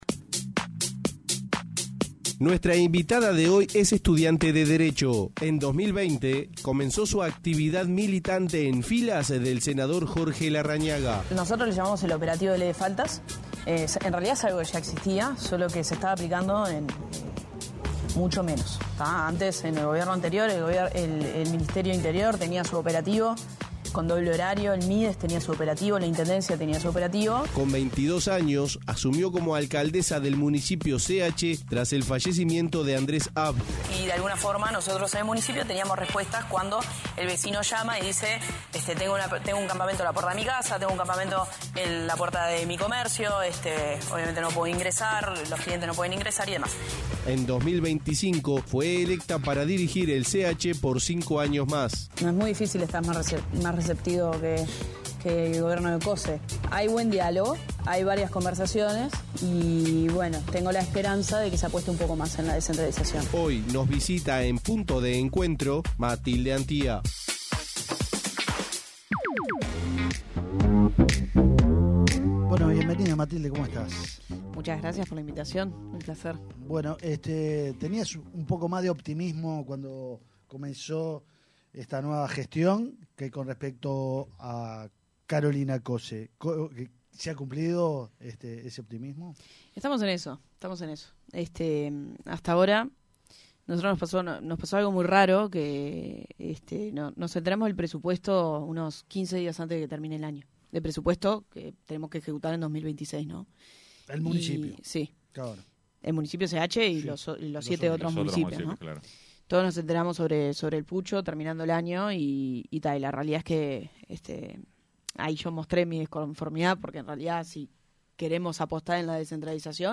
En entrevista con Punto de Encuentro, la alcaldesa del Municipio CH, Matilde Antía comparó el vínculo sobre el tema seguridad con el anterior y con el actual Ministerio del Interior. Indicó que su contacto directo no es con Carlos Negro sino con el director de Convivencia, Víctor Abal.